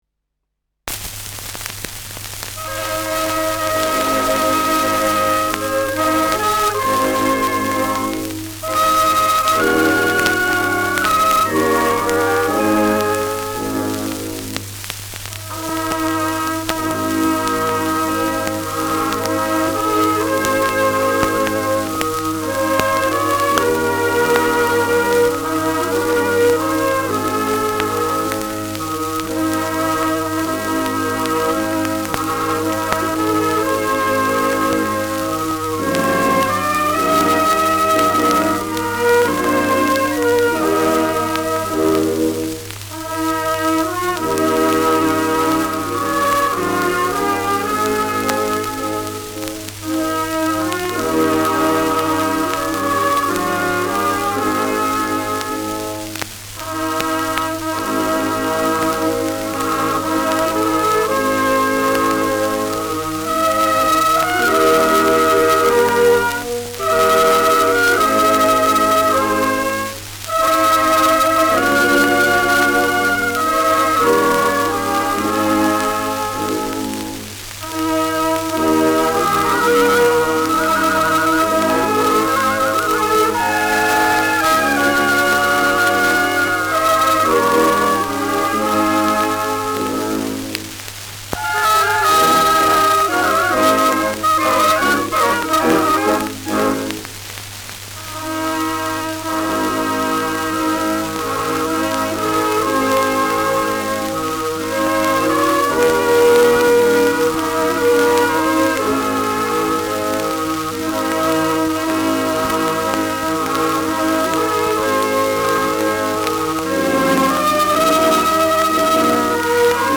Schellackplatte
Juchzer am Ende.